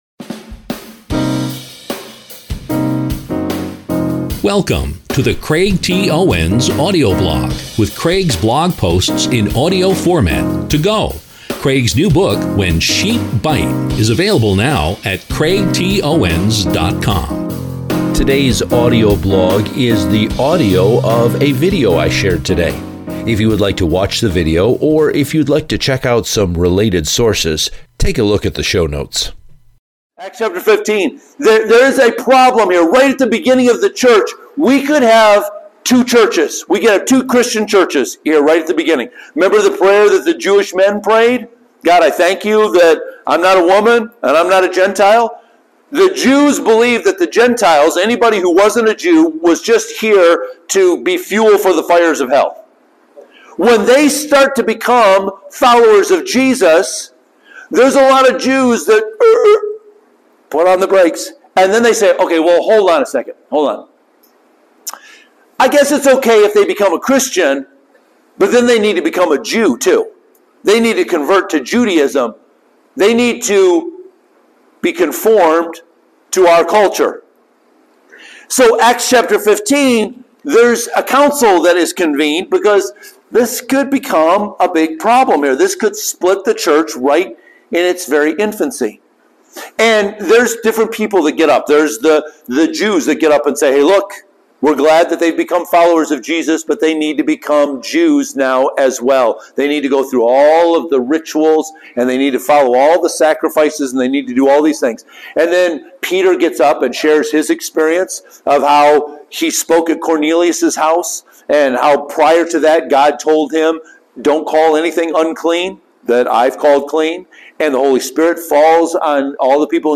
This is a clip from a sermon I shared called Unity Is Not Conformity.